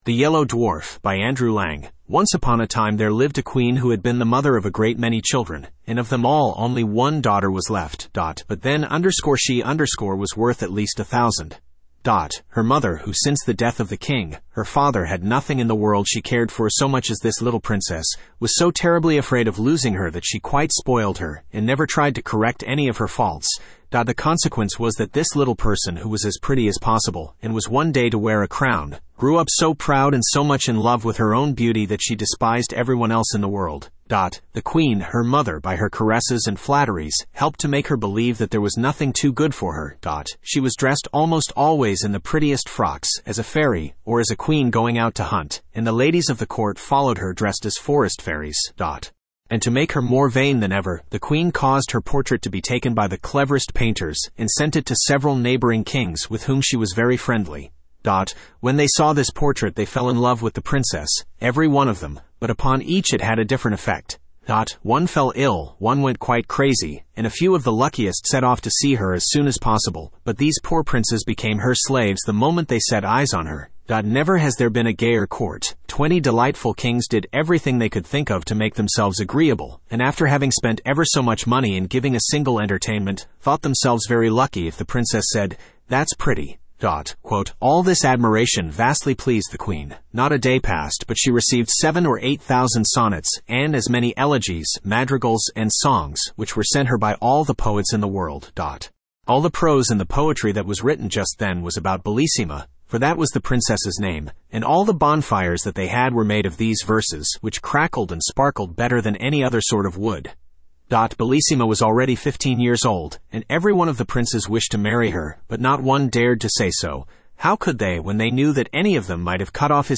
Spoken Ink « The Blue Fairy Book The Yellow Dwarf Studio (Male) Download MP3 Once upon a time there lived a queen who had been the mother of a great many children, and of them all only one daughter was left.
the-yellow-dwarf-en-US-Studio-M-e38574cc.mp3